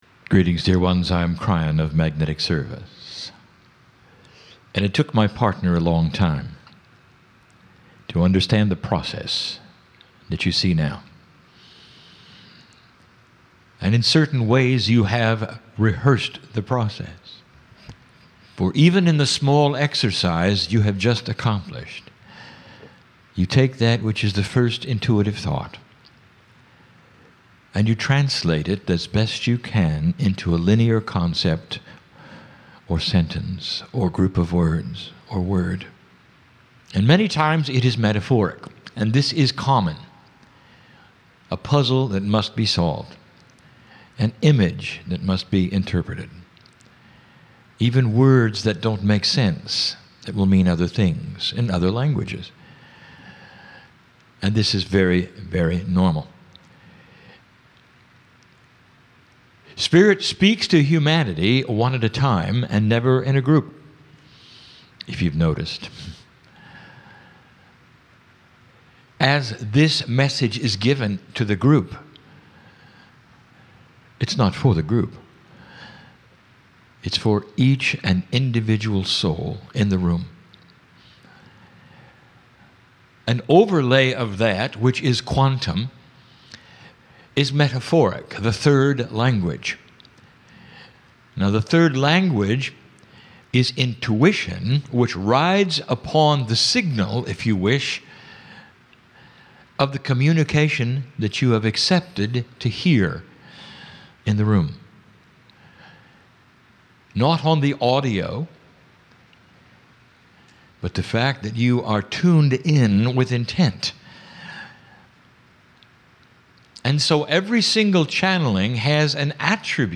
28 minute channelling session
Live Channel for Kryon
INSTRUCTIONS: 42.2 megabytes 28 minutes High-quality Stereo - MP3 Filename: "GUIDES&ANGELS.mp3" PC - Right-click the left image to download the file.